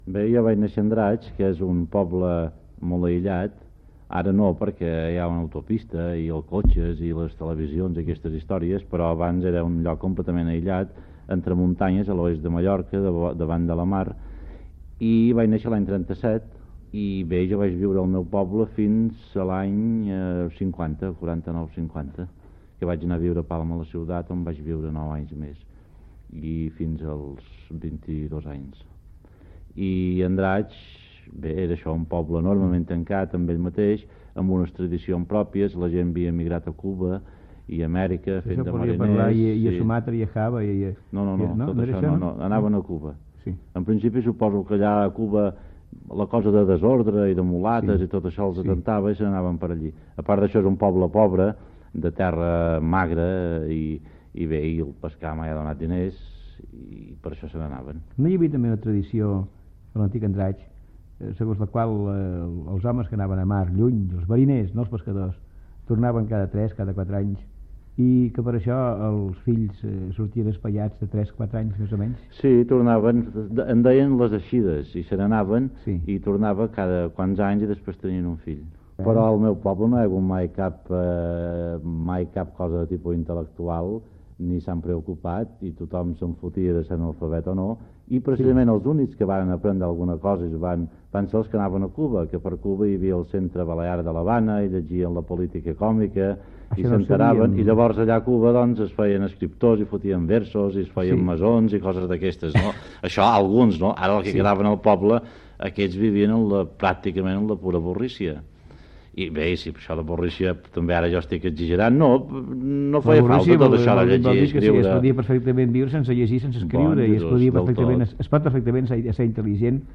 Entrevista a Baltasar Porcel. S'hi parla del poble d'Andratx (Mallorca), Cuba, la seva família